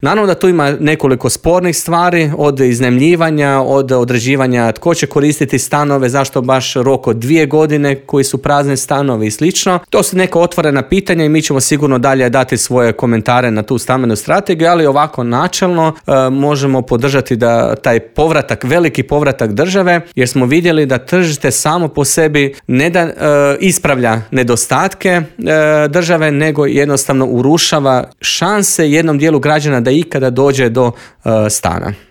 Prate li to socijalne mjere i može li se siromaštvo prevenirati - neke su od teme o kojima smo u Intervjuu Media servisa razgovarali